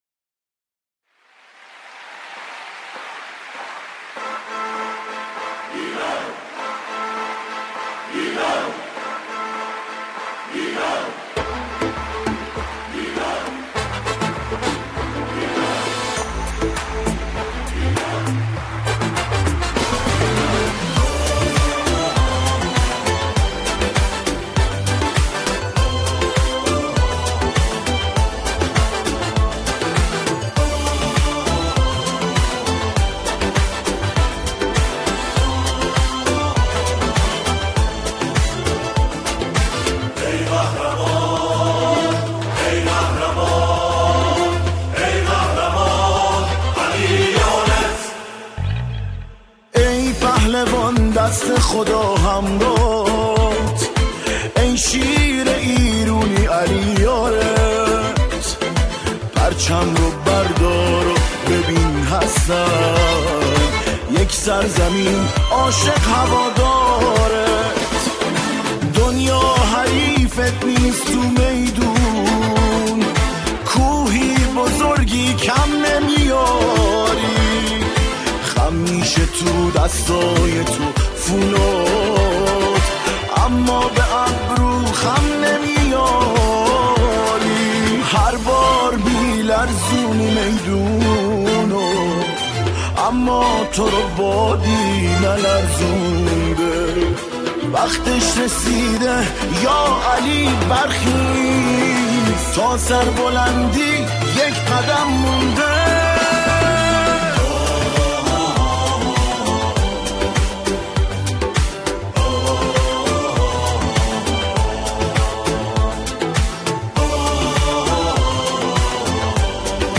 سرودهای ورزشی
در این قطعه، شعری با موضوعی ورزشی همخوانی می‌شود.